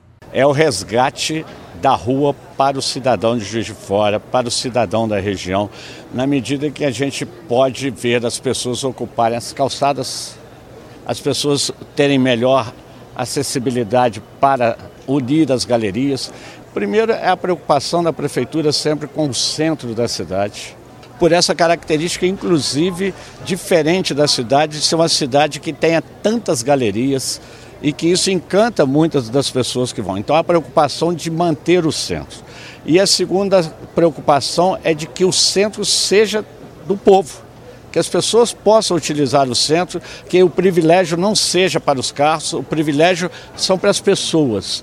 prefeito Antônio Almas